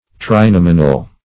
Meaning of trinominal. trinominal synonyms, pronunciation, spelling and more from Free Dictionary.
Search Result for " trinominal" : The Collaborative International Dictionary of English v.0.48: Trinominal \Tri*nom"i*nal\, n. & a. [Pref. tri- + L. nomen, nominis, name: cf. L. trinominis three-named.]